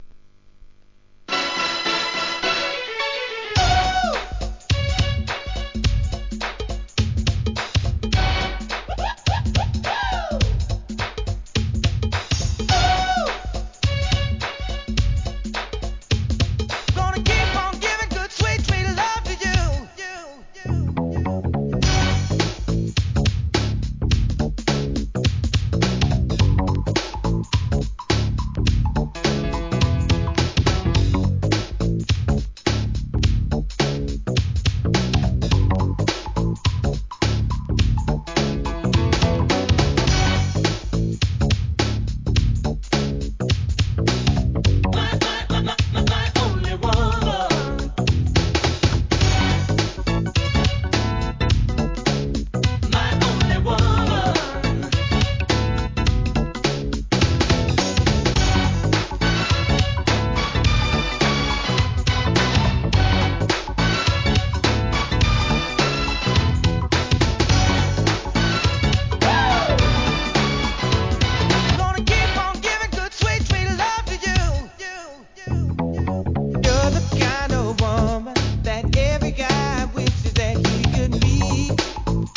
HIP HOP/R&B
1990年、抜群のコーラス・ワークで聴かせるNEW JACK SWING!!